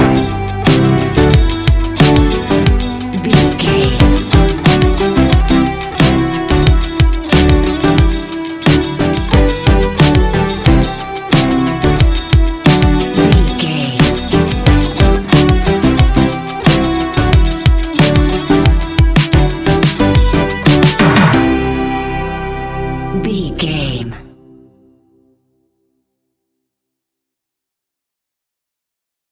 Ionian/Major
ambient
electronic
new age
downtempo
pads